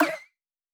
Buzz Error (11).wav